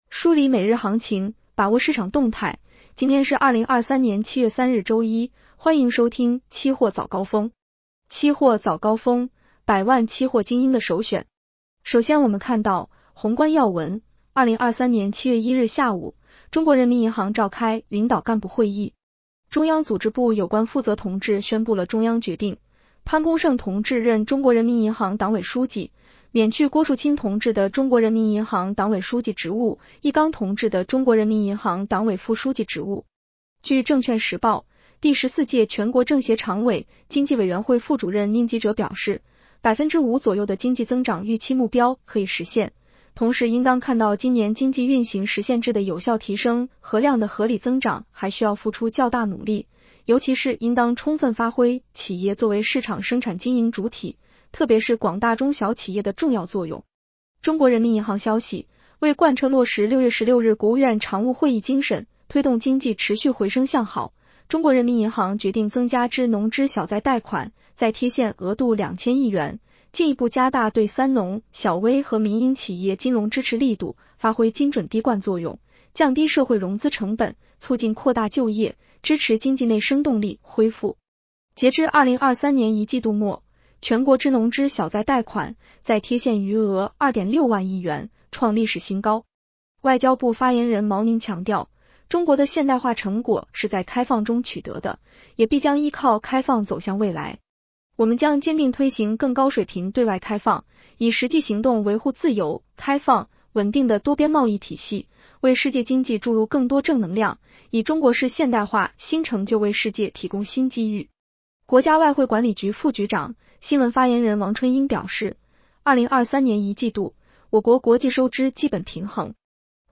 【期货早高峰-音频版】 女声普通话版 下载mp3 宏观要闻 1. 2023年7月1日下午，中国人民银行召开领导干部会议。